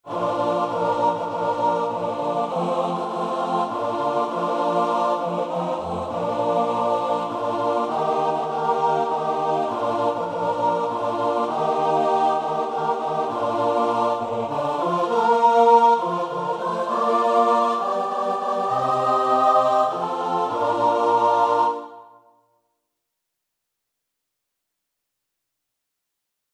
Free Sheet music for Choir
Traditional Music of unknown author.
Bb major (Sounding Pitch) (View more Bb major Music for Choir )
3/4 (View more 3/4 Music)
Christian (View more Christian Choir Music)